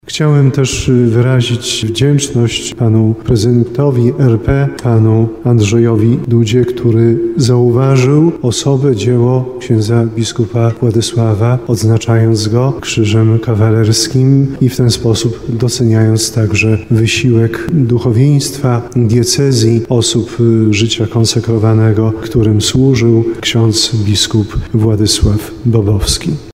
Ordynariusz diecezji tarnowskiej poinformował o nadaniu orderu na zakończenie Mszy św. w Światowym Dniu Życia Konsekrowanego: -Chciałem wyrazić wdzięczność Prezydentowi RP, panu Andrzejowi Dudzie, który zauważył osobę i dzieło księdza biskupa Władysława, odznaczając go Krzyżem Kawalerskim.